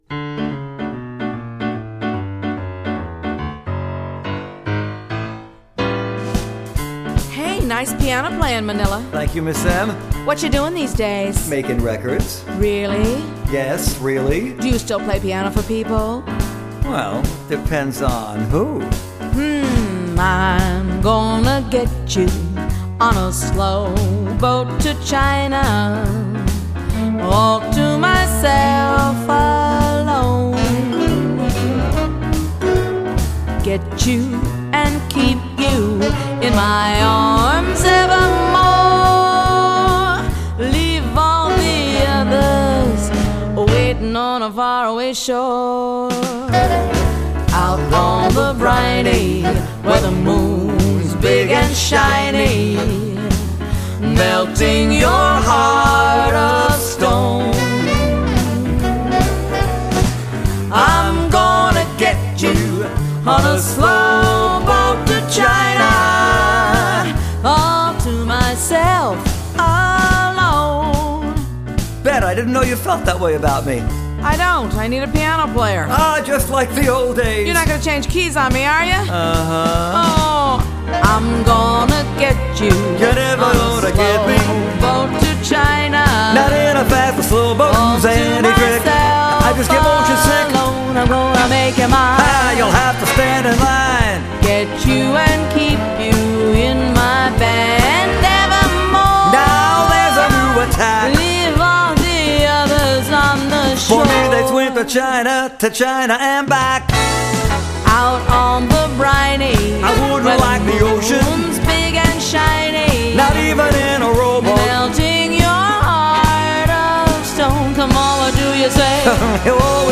It also has two terrific duets on it.
This one is just plain cute.